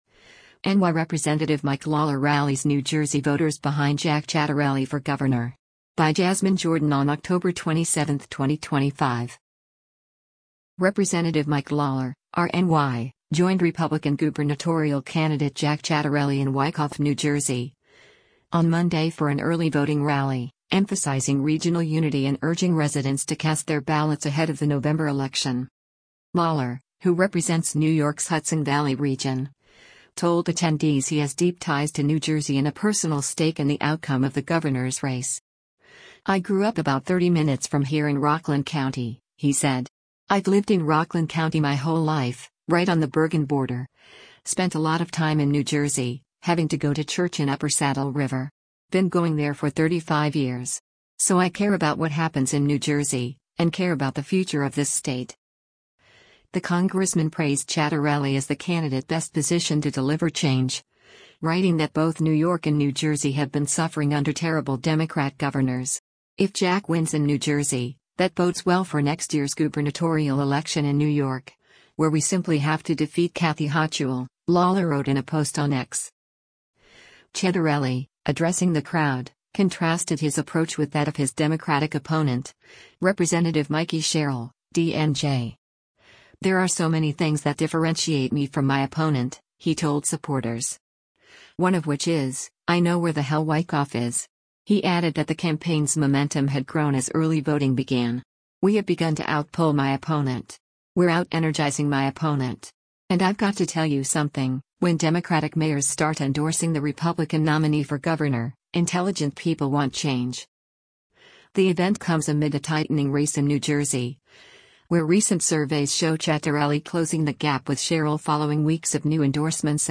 NY Rep. Mike Lawler Rallies New Jersey Voters Behind Jack Ciattarelli for Governor
Rep. Mike Lawler (R-NY) joined Republican gubernatorial candidate Jack Ciattarelli in Wyckoff, New Jersey, on Monday for an early voting rally, emphasizing regional unity and urging residents to cast their ballots ahead of the November election.